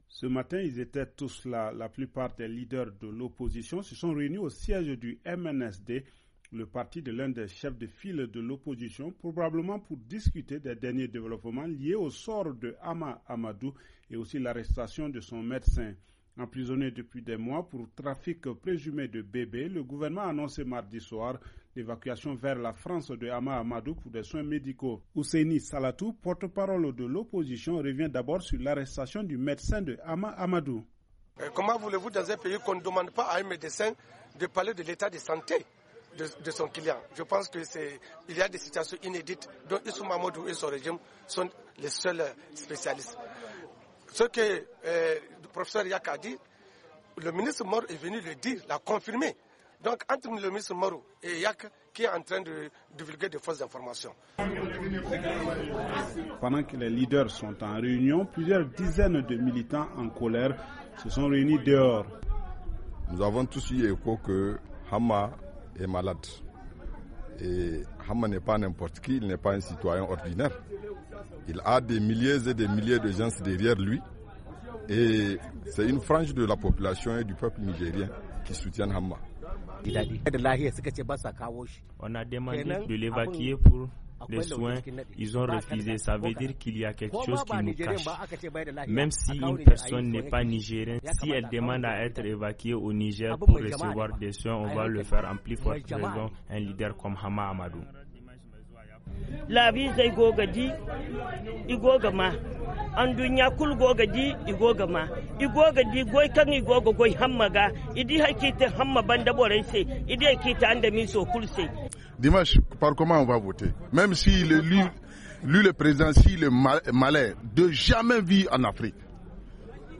Reportage
depuis Niamey